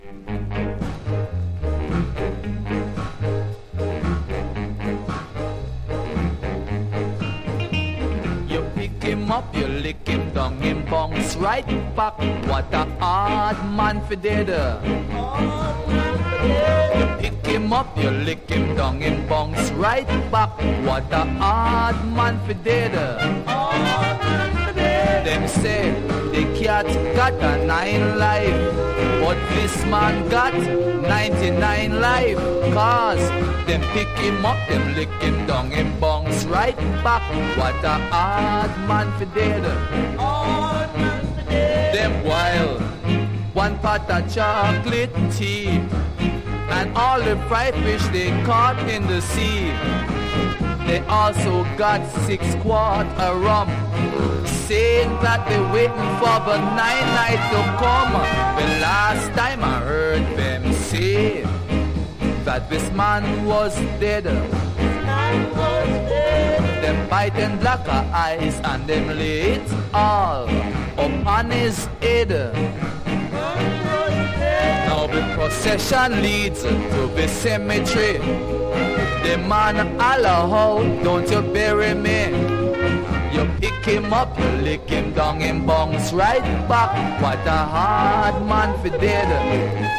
1. REGGAE >